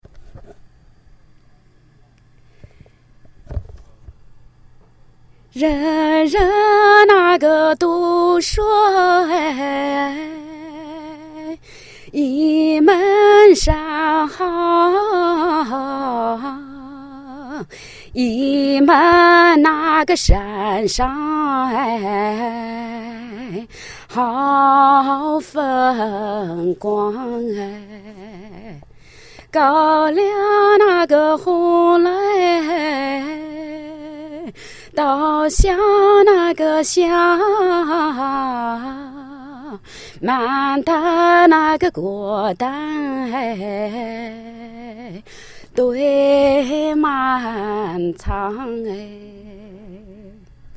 Song,